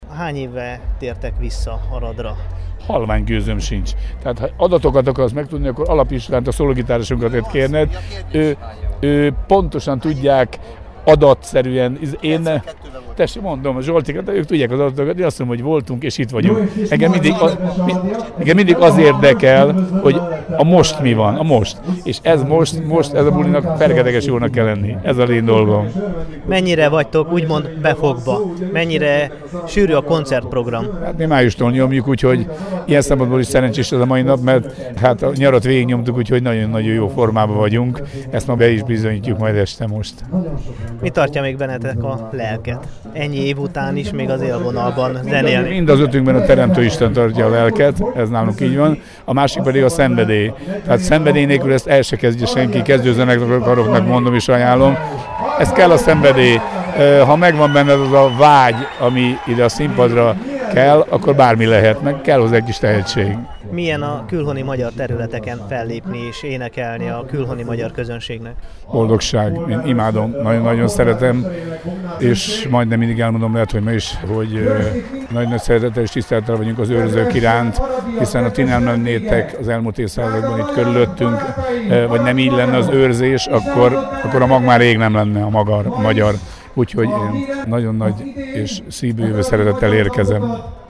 A fellépés előtt a frontember, Pataki Attila adott interjút az Aradi Híreknek.